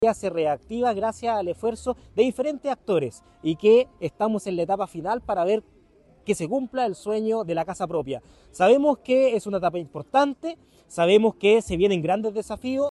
Carlos Lillo – Alcalde de Salamanca